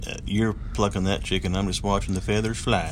plucking.mp3